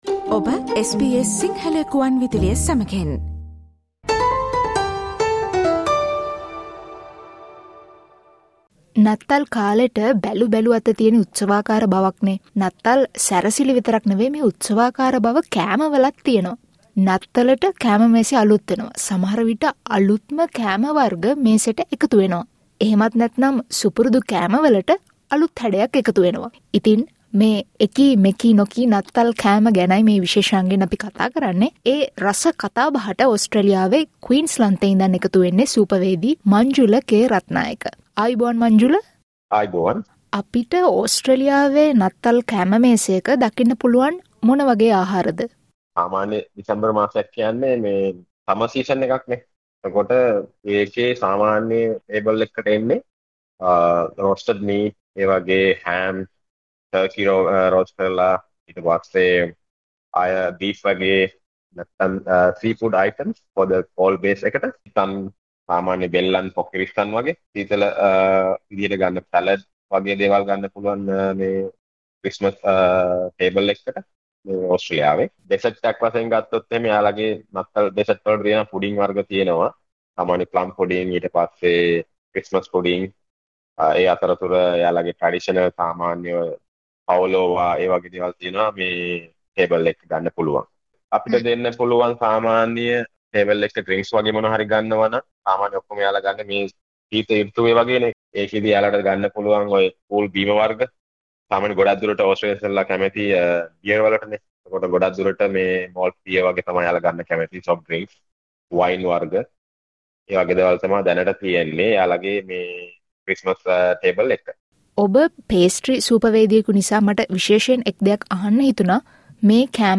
ඉතින් ඕස්ට්‍රේලියාවේ නත්තල් කෑම වර්ග සහ ඊට සාපේක්ෂව ශ්‍රී ලංකාවේ නත්තල් කෑම පිළිබඳ SBS සිංහල සේවය ගෙන එන කතාබහට ඔබත් සවන් දෙන්න.